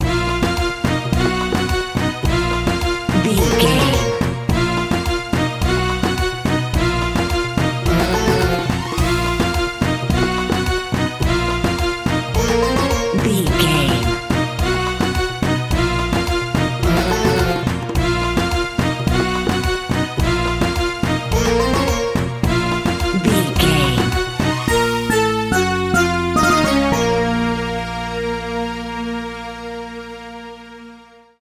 Aeolian/Minor
B♭
World Music
percussion